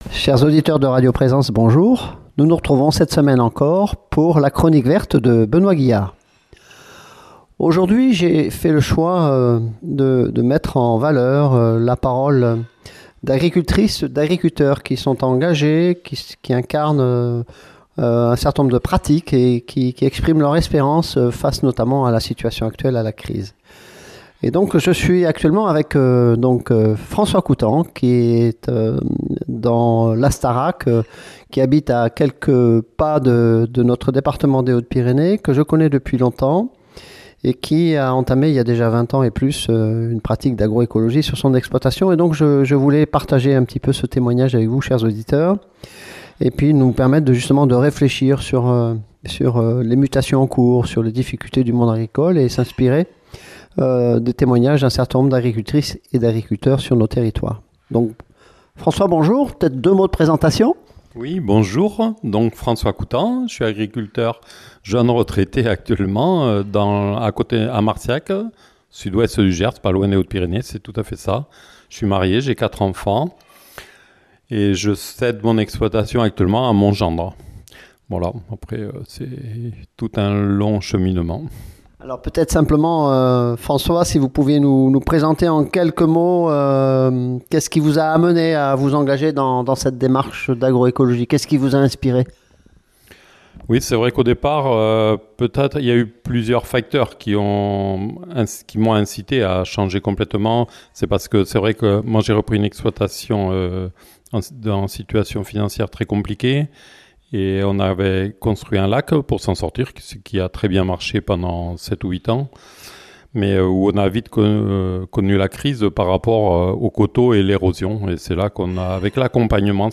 Dans cette interview, il témoigne d’un chemin patient fait d’observation, d’expérimentation et de respect du vivant, où la terre, les plantes et les animaux retrouvent leur juste place. Un récit simple et profond qui montre qu’une autre manière de cultiver est possible, durable et porteuse d’espérance pour un monde agricole en crise et qui cherche une autre voie.